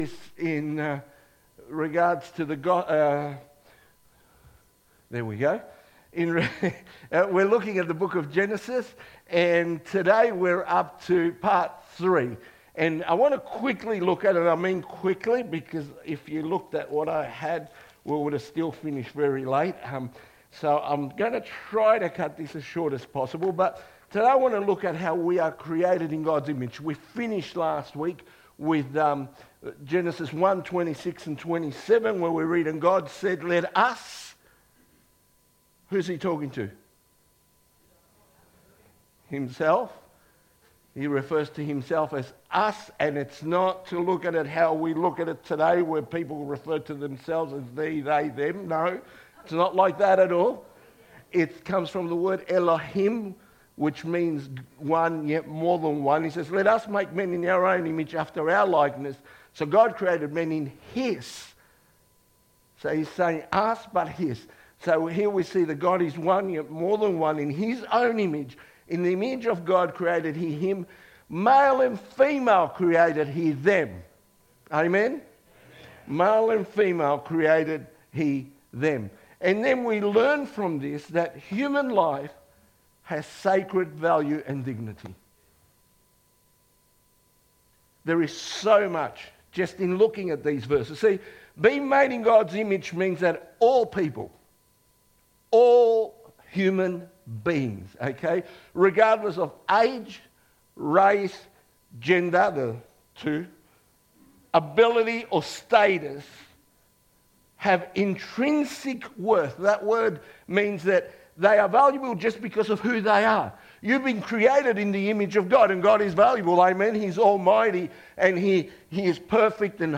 Wonthaggi Baptist Church
2025 • 19.25 MB Listen to Sermon Download this Sermon Download this Sermon To download this sermon